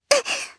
Hanus-Vox_Damage_jp_02.wav